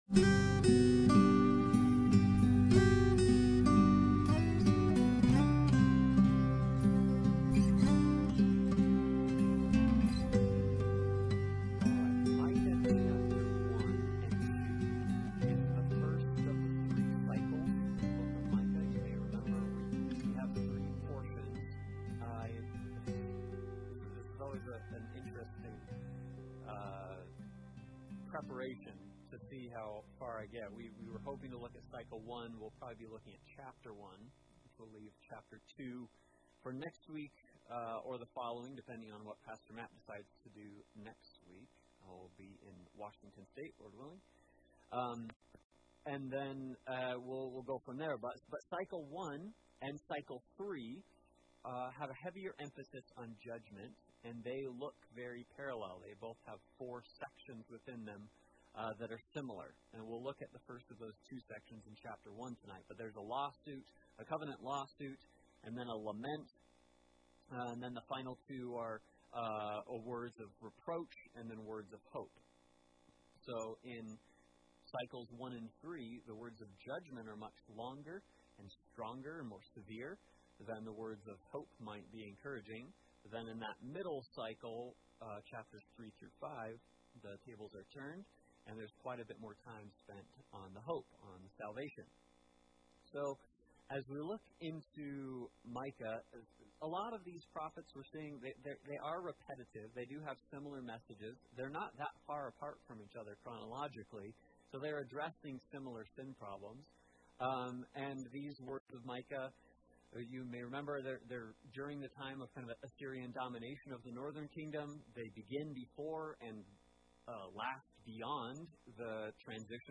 Service Type: Sunday Bible Study